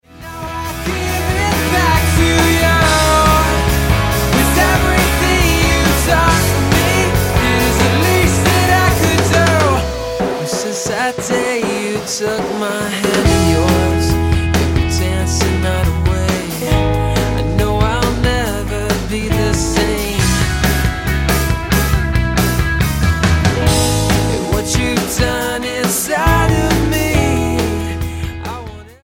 STYLE: Rock
singing and playing guitar, piano and keyboards